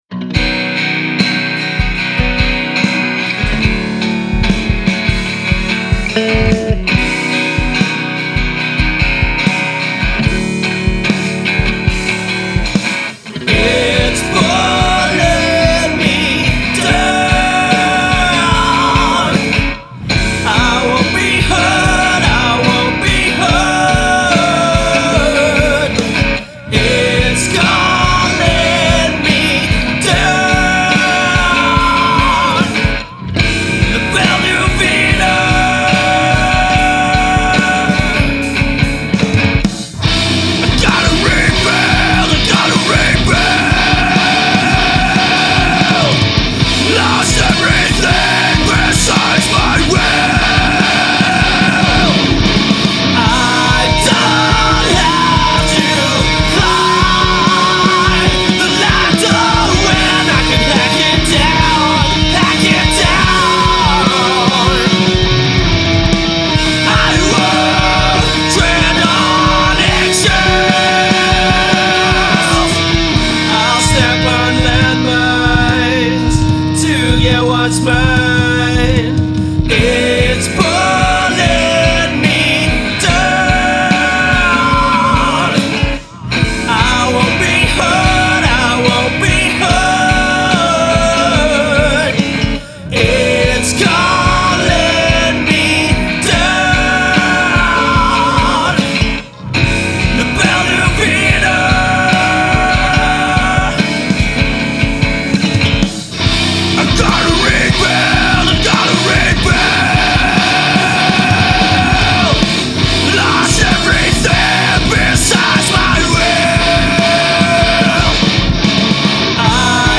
A friends band kindly let me do a recording of them, so here it is.
The track was clipping pretty much all the way through.
It sounds like MIDI drums, which its completely fine for some...but IMO, I just dont like it.
As for the drums: The drummer of that band has very detuned/box like drums, with no real reverb at all.
In the intro, the drums seem a bit out of time.